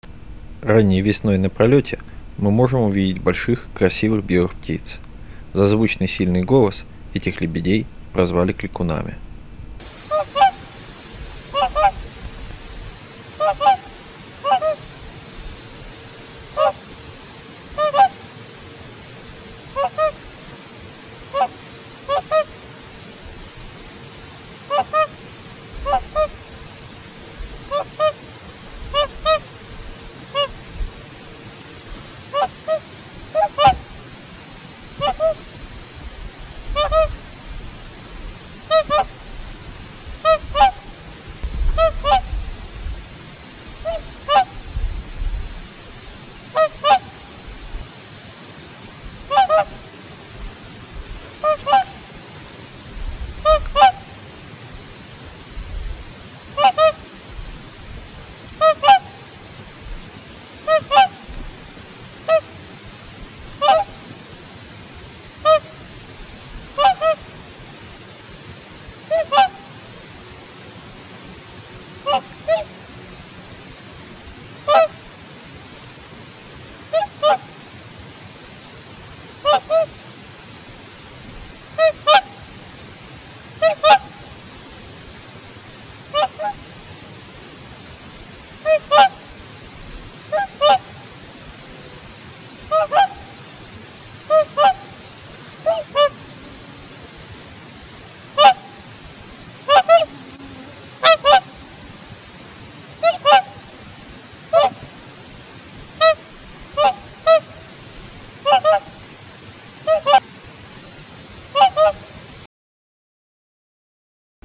ЛЕБЕДЬ-КЛИКУН (Cygnus musicus Bechst)
Голос громкий, трубный, слышен на очень большое расстояние. Это звучный крик.